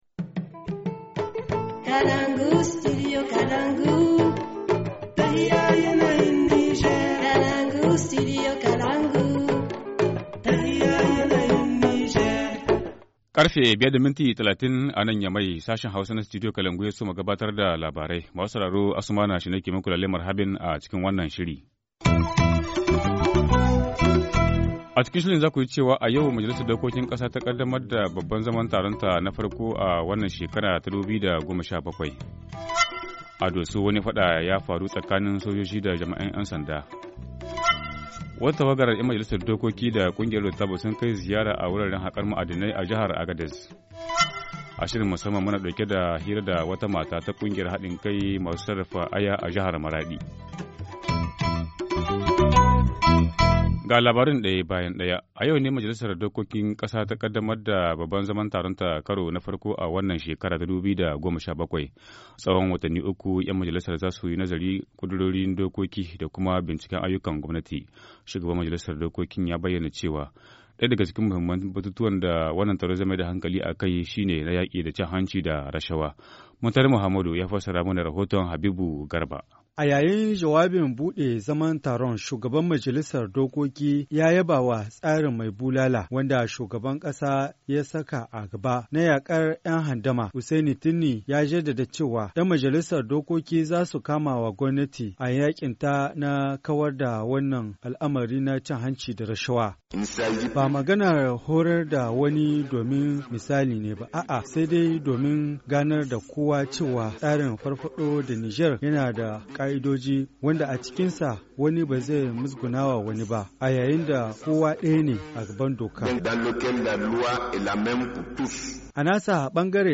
2. Rixe entre policiers et militaires, dans la nuit de jeudi à vendredi à Dosso soldée par la mort d’un militaire. Des précisions avec le Gouverneur de la région de Dosso.
Journal en français